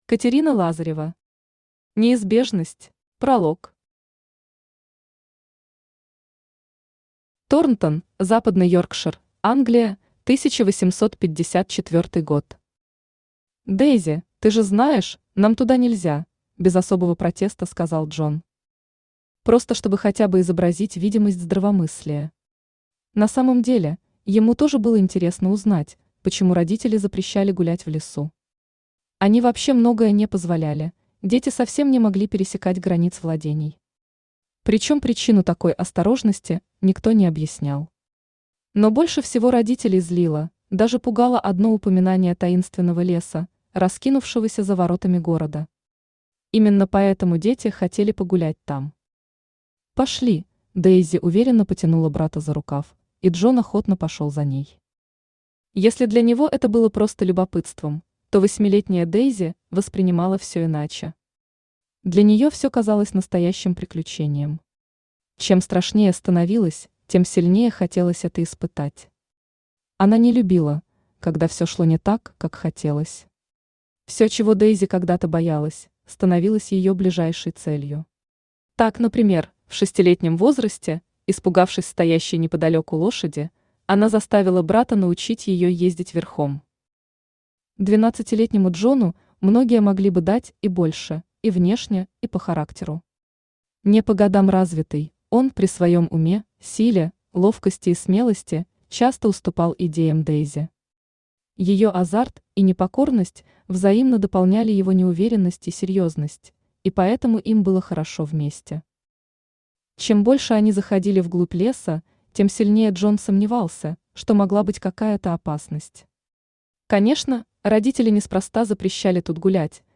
Аудиокнига Неизбежность | Библиотека аудиокниг
Aудиокнига Неизбежность Автор Катерина Лазарева Читает аудиокнигу Авточтец ЛитРес.